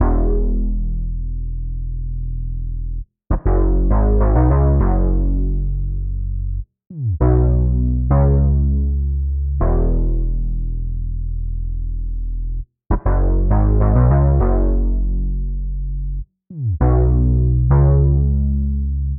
Подскажите вст синты,где есть такие басы.